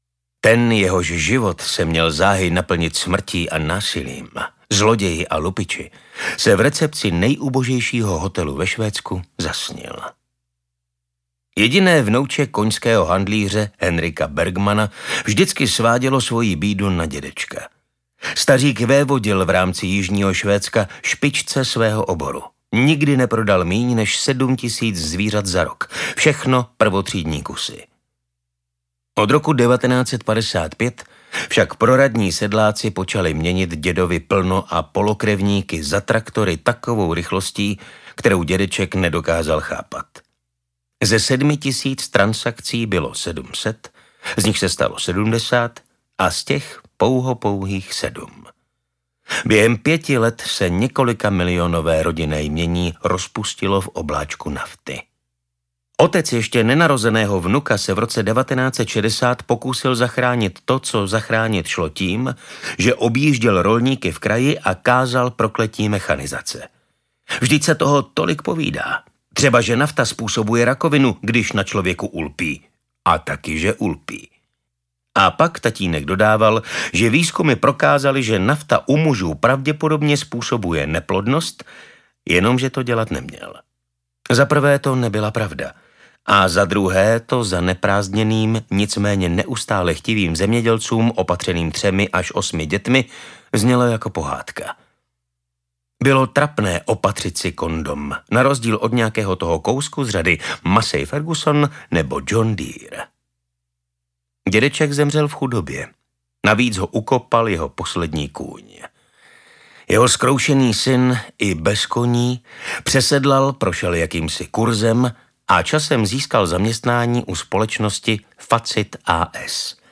Audio knihaZabiják Anders a jeho přátelé (a sem tam nepřítel)
Ukázka z knihy
zabijak-anders-a-jeho-pratele-a-sem-tam-nepritel-audiokniha